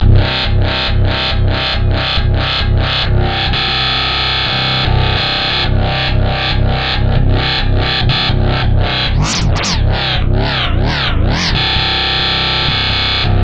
WobbleVowel Bass Line 819a
描述：WobbleVowel bass line
标签： 143 bpm Dubstep Loops Bass Wobble Loops 2.46 MB wav Key : Unknown
声道立体声